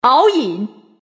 王者荣耀_人物播报_敖隐.mp3